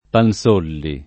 [ pan S1 lli o pan S0 lli ]